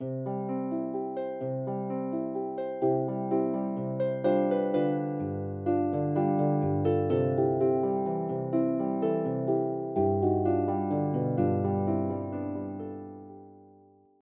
traditional Neapolitan song
is for solo lever or pedal harp